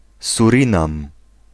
Ääntäminen
Vaihtoehtoiset kirjoitusmuodot Surinam Ääntäminen US UK : IPA : /ˌsʊə.ɹɪˈnæm/ IPA : /ˌsjʊə.ɹɪˈnæm/ IPA : /ˈsʊə.ɹɪ.næm/ US : IPA : /ˌsʊɹ.ɪˈnɑm/ IPA : /ˈsʊɹ.ɪ.nɑm/ IPA : /ˈsʊɹ.ɪ.næm/ Lyhenteet (laki) Surin.